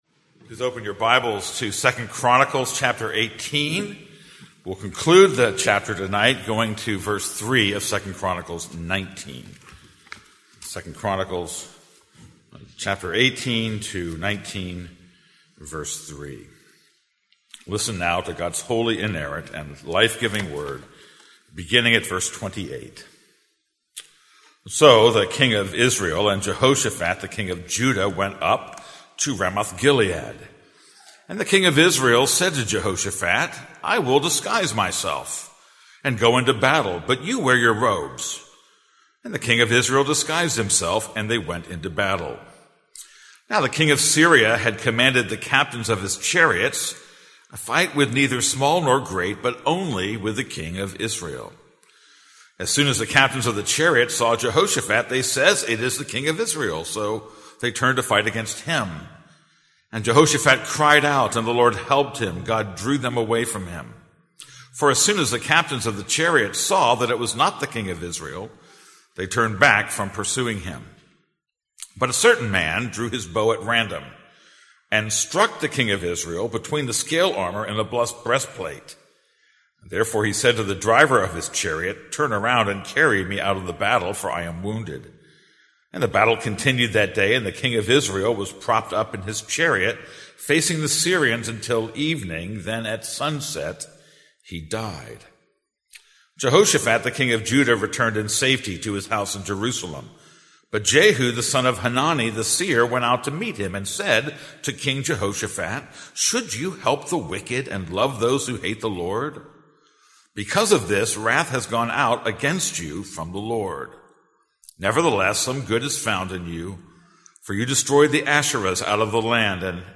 This is a sermon on 2 Chronicles 18:28-19:3.